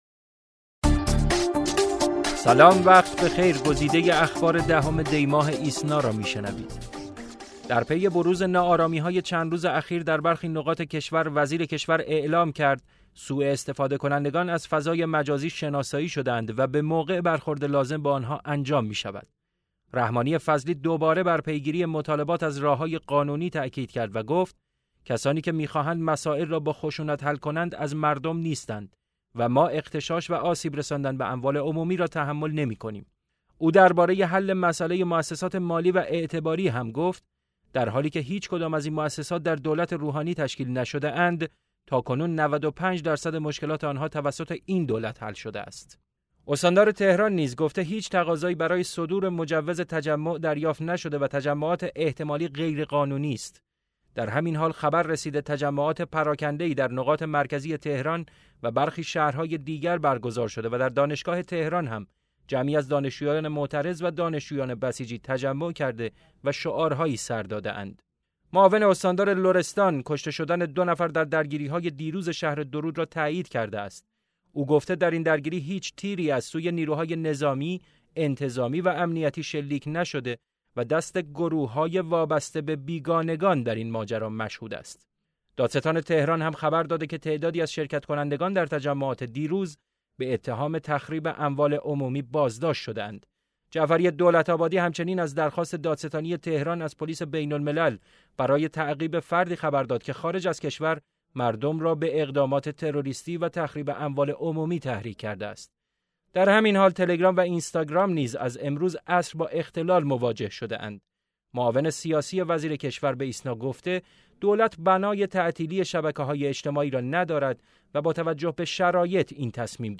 صوت / بسته خبری ۱۰ دی ۹۶